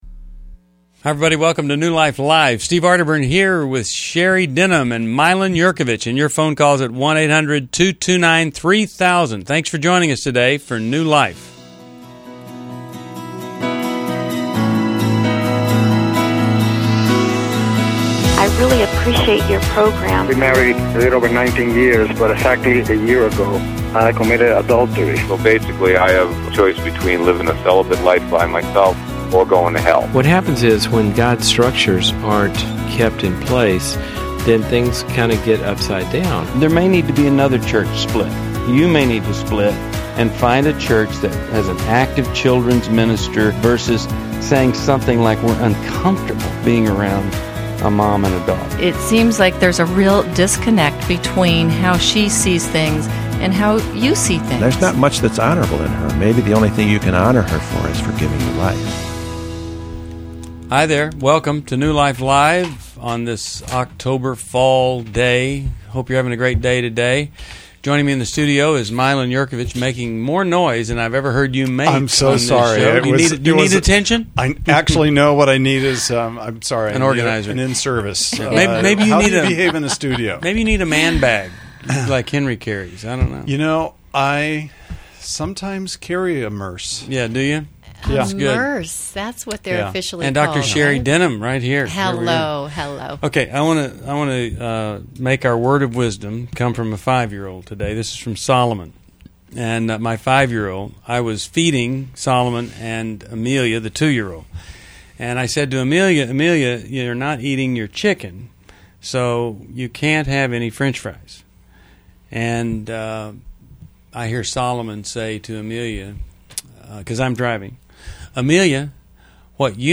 Explore vital topics like reconciliation, forgiveness, and grief as our hosts answer tough caller questions on parenting and relationships.